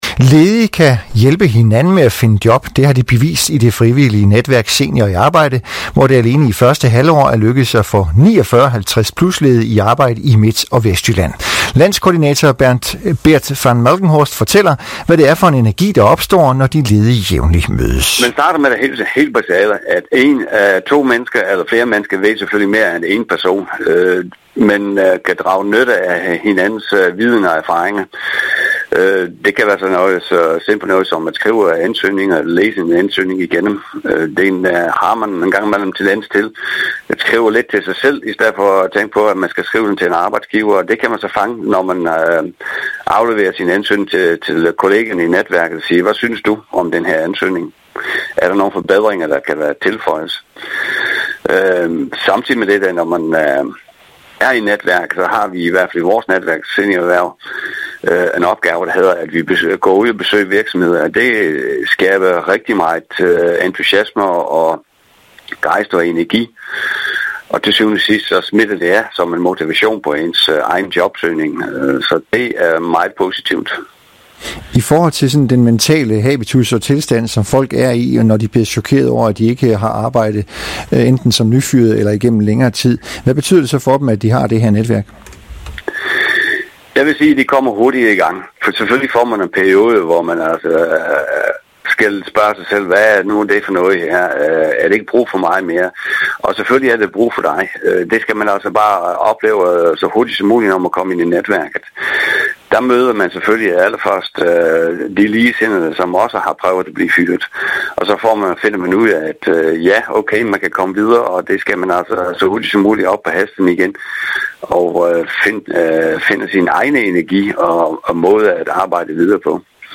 Radioindslag: Radio MidtVest